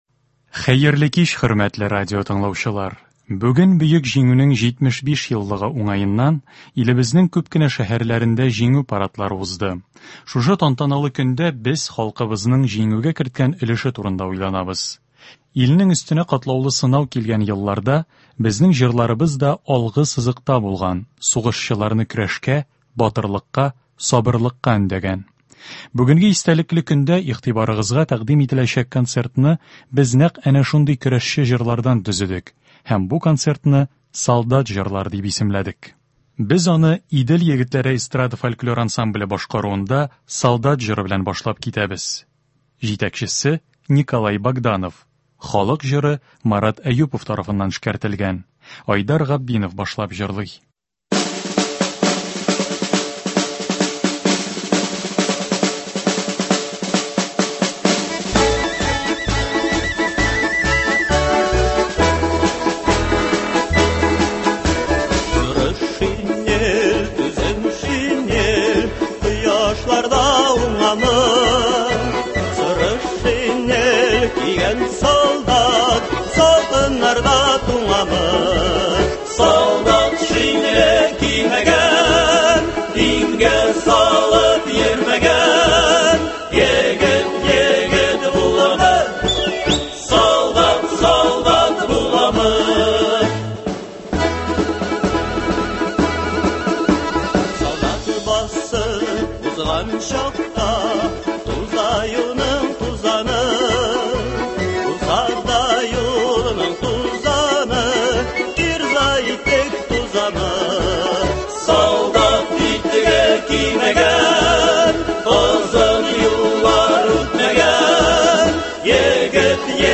Концертлар.